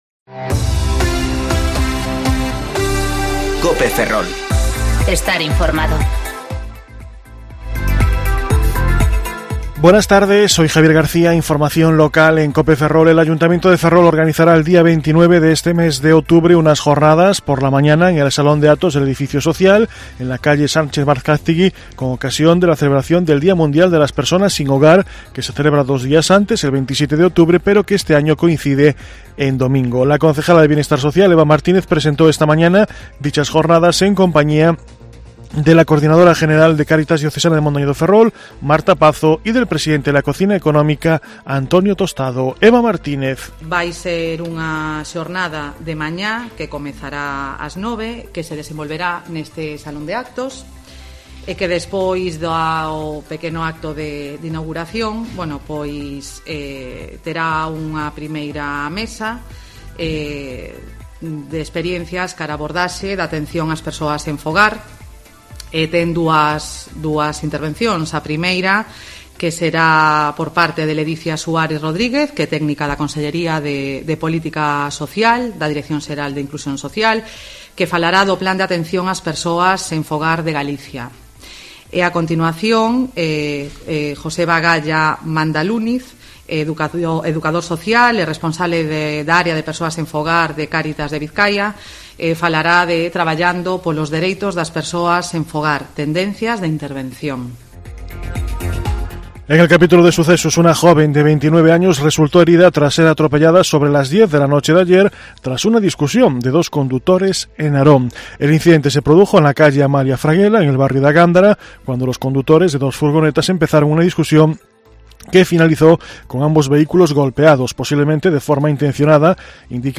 Informativo Mediodía Cope Ferrol 3/10/2019 (De 14.20 a 14.30 horas)